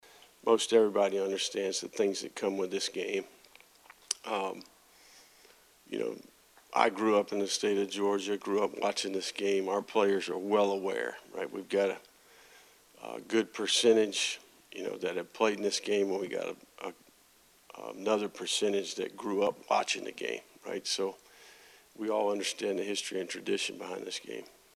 As the Florida Gators prepare for the No. 1 team in the country, the Georgia Bulldogs, and the iconic Georgia-Florida game on Saturday, Oct. 29, Gators’ head coach Billy Napier spoke with media on Oct. 24.
The Gators’ head coach spoke about his players’ mentality in the days leading up to the classic meeting.